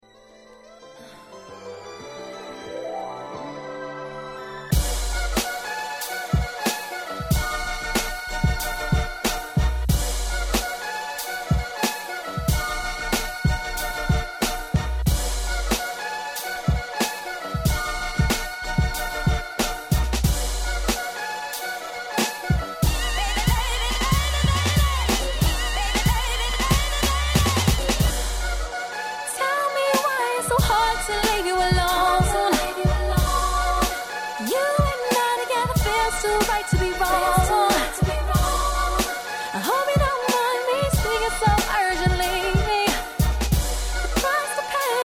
02' Smash Hit R&B !!
何て言うんでしょ、この『夕暮れ感』、最高に気持ち良いです。